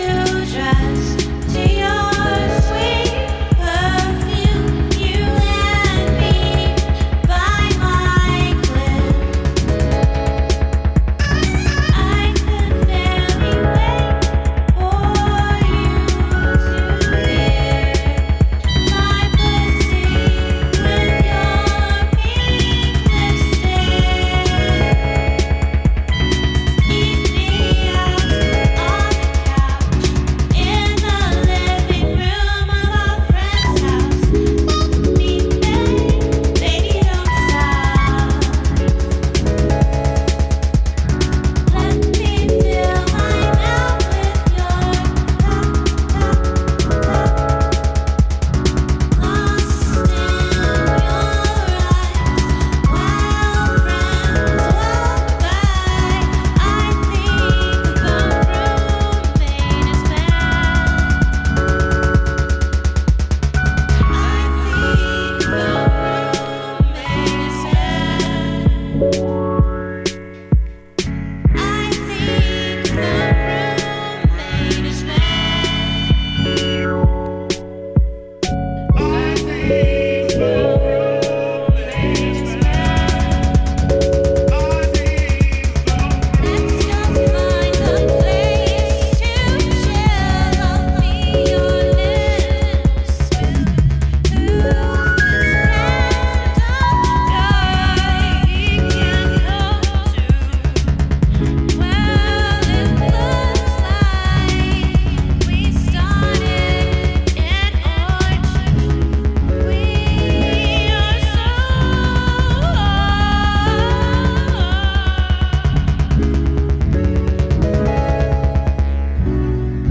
Electro Techno